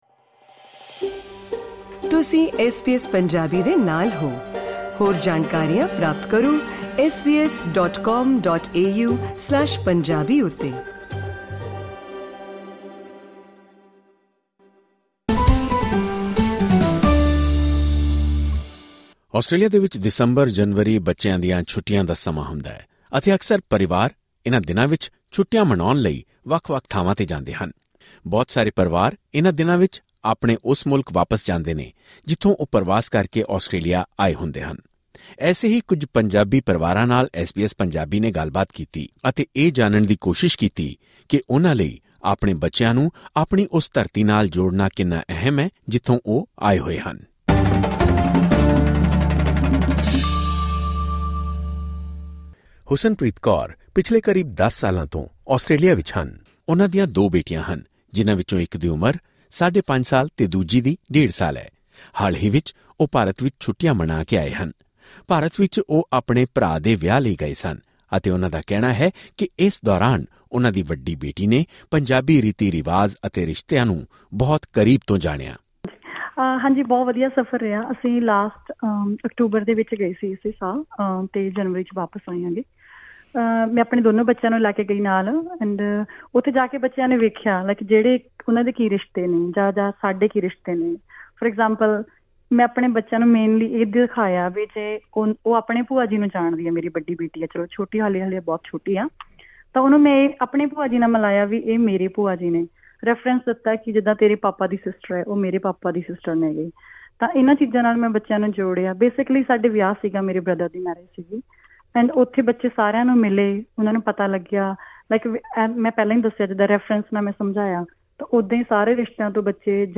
ਆਸਟ੍ਰੇਲੀਆ ਵਿੱਚ ਗਰਮੀ ਦੀਆਂ ਛੁੱਟੀਆਂ ਹੋਣ ਦੇ ਬਾਵਜੂਦ, ਕਈ ਪਰਵਾਸੀ ਪਰਿਵਾਰ ਉੱਤਰੀ ਭਾਰਤ ਦੀ ਕੜਾਕੇ ਦੀ ਠੰਡ ਵਿਚ ਵੀ ਆਪਣੇ ਵਤਨ ਜਾਣ ਨੂੰ ਤਰਜੀਹ ਦਿੰਦੇ ਹਨ। ਇਸ ਯਾਤਰਾ ਦੇ ਪਿੱਛੇ ਮਕਸਦ ਹੁੰਦਾ ਹੈ ਆਸਟ੍ਰੇਲੀਆ ਵਿੱਚ ਪਲ ਰਹੇ ਬੱਚਿਆਂ ਨੂੰ ਆਪਣੀ ਮੂਲ ਧਰਤੀ, ਭਾਸ਼ਾ ਅਤੇ ਸਭਿਆਚਾਰ ਨਾਲ ਜੋੜ ਕੇ ਰੱਖਣਾ। ਐਸ ਬੀ ਐਸ ਪੰਜਾਬੀ ਨੇ ਛੁੱਟੀਆਂ ਦੌਰਾਨ ਪੰਜਾਬ ਜਾਣ ਵਾਲੇ ਕੁਝ ਪਰਿਵਾਰਾਂ ਨਾਲ ਗੱਲਬਾਤ ਕਰਕੇ ਉਹਨਾਂ ਦੀ ਸੋਚ, ਅਨੁਭਵ ਅਤੇ ਭਾਵਨਾਵਾਂ ਇਸ ਪੌਡਕਾਸਟ ਰਾਹੀਂ ਸਾਹਮਣੇ ਲਿਆਂਦੀਆਂ ਹਨ।